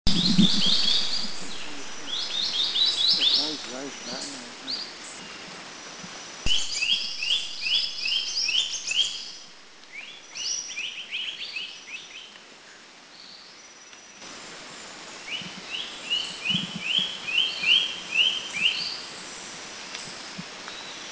Rufous Paradise-Flycatcher  Terpsiphone cinnamomea  Country endemic
B2A_RufousParadiseFlycatcherMakiling210_SDW.mp3